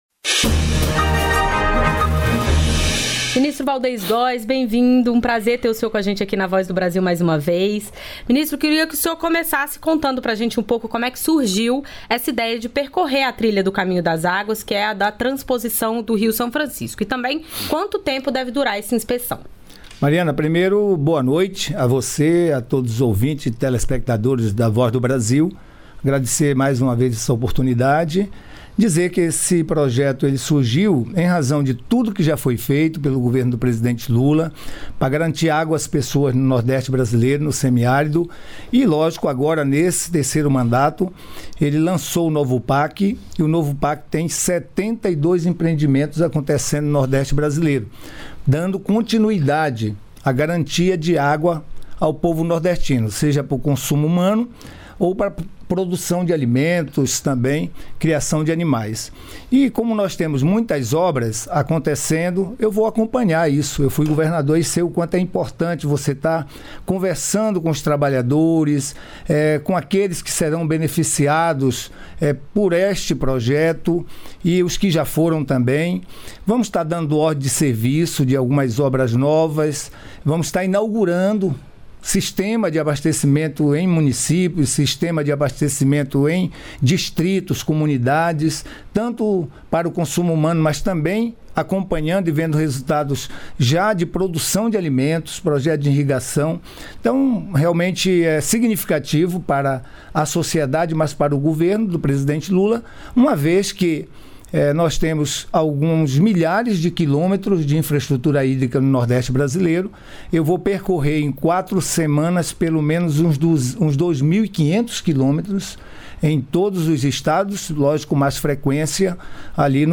Em entrevista, secretário desmente onda de notícias falsas sobre taxação do PIX que invadiu as redes sociais.